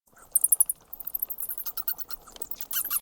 bat1.ogg